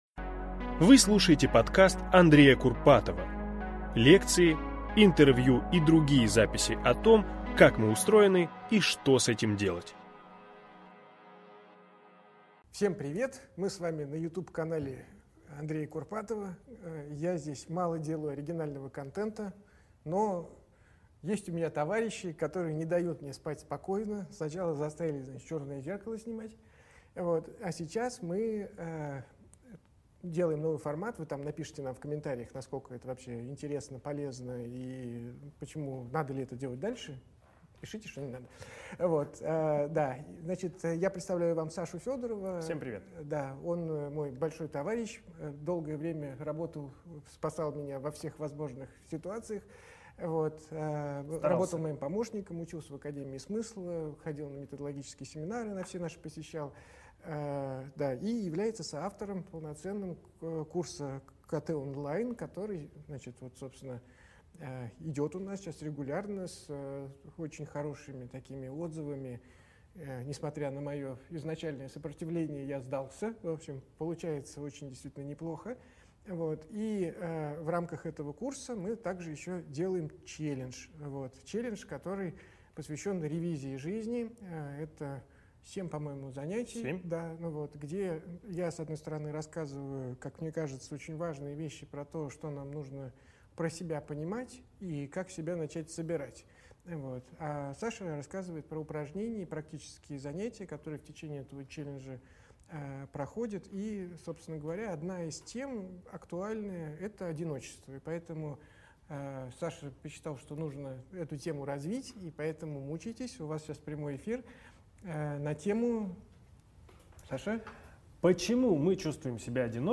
Аудиокнига Как избавиться от чувства одиночества? Запись live-трансляции с Андреем Курпатовым | Библиотека аудиокниг